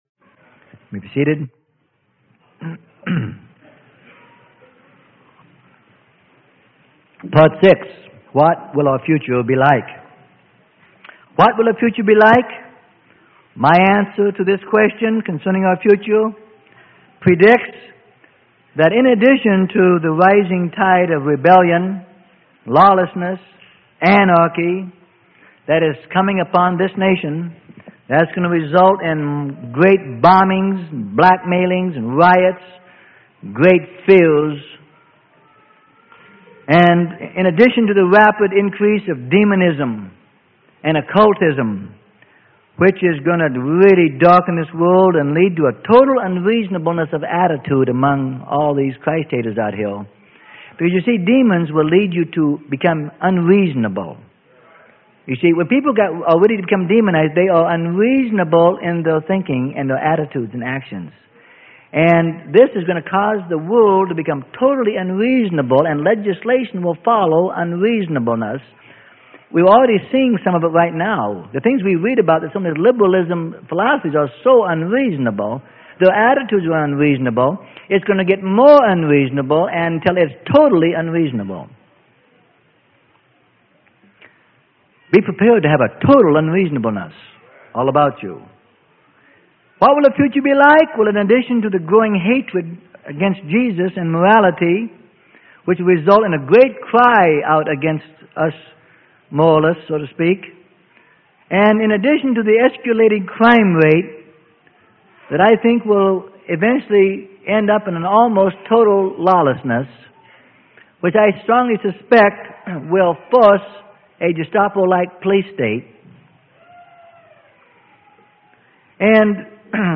Sermon: What Will Our Future Be Like - Part 06 - Freely Given Online Library